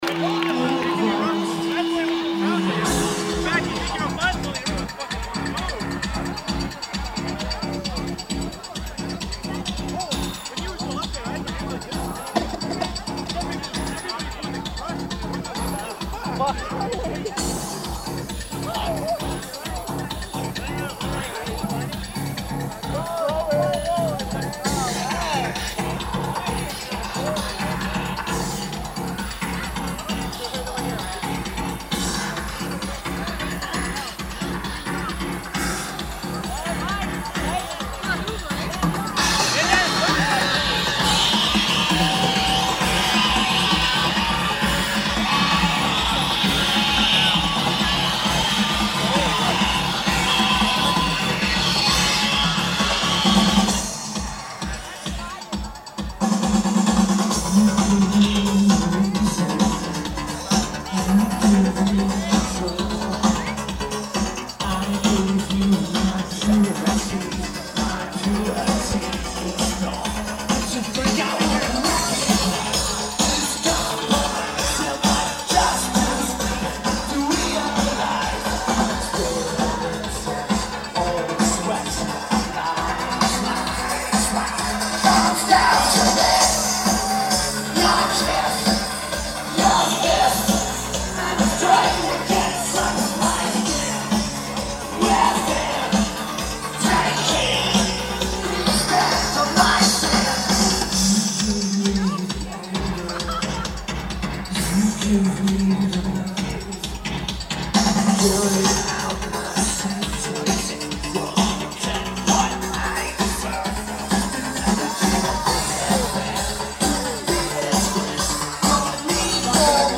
Lineage: Audio - AUD, Unknown DAT Recording